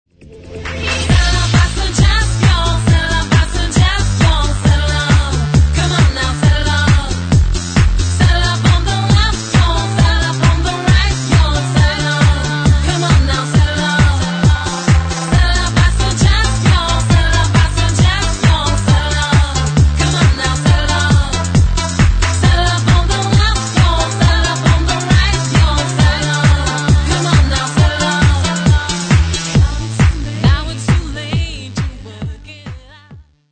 Or any ideas who is the woman in the vocals?